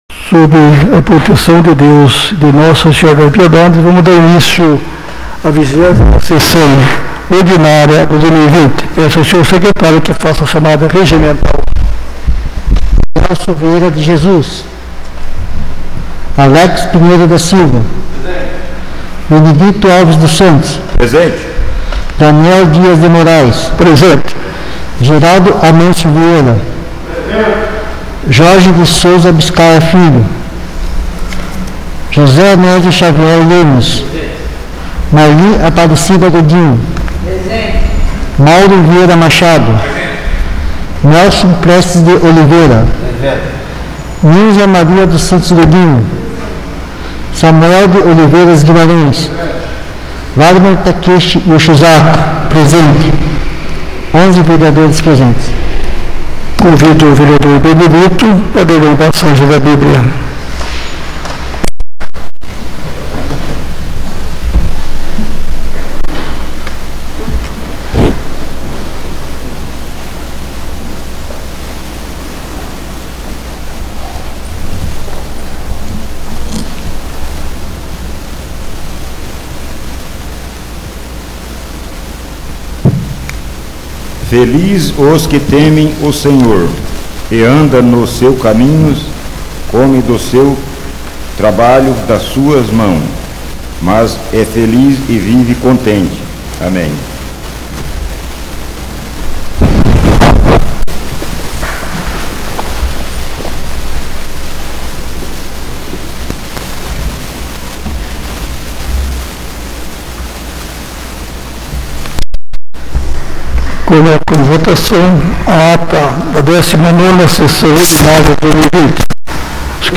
20ª Sessão Ordinária de 2020